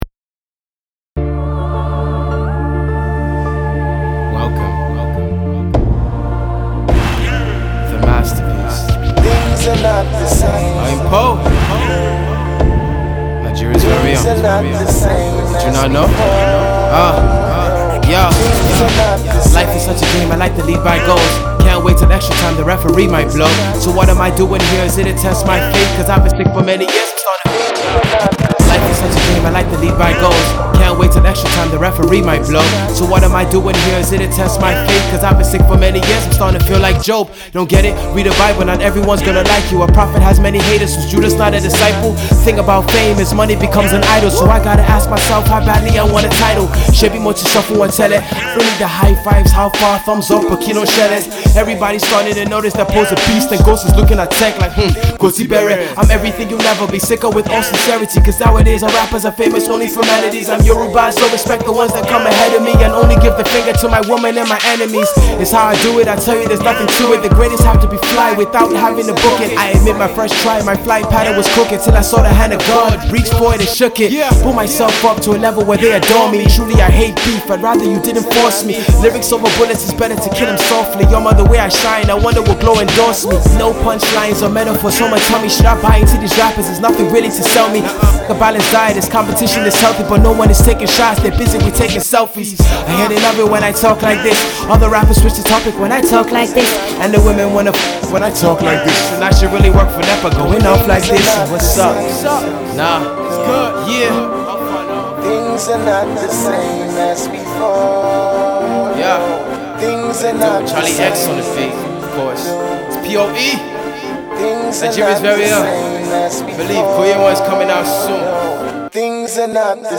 Laced with the intelligent punchlines and witty wordplay